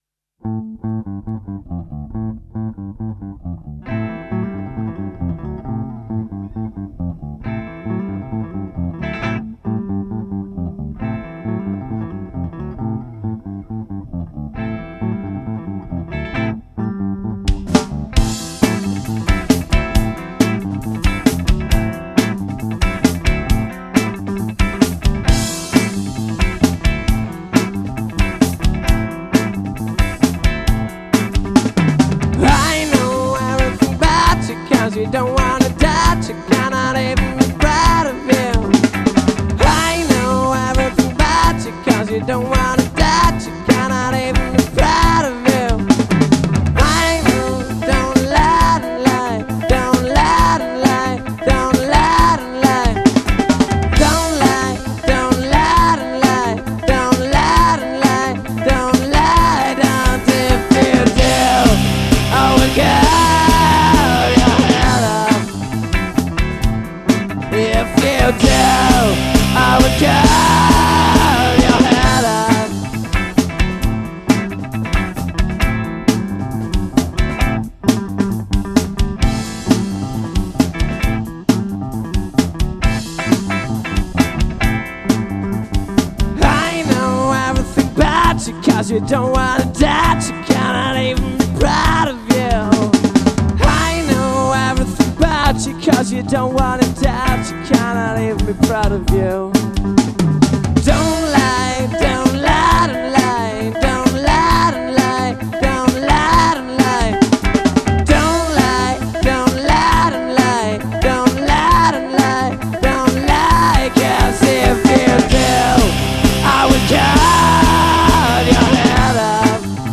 Ein donnernder Bass, der trotzdem flexibel klingt.
Vocals & guitar
Guitar & backing vocals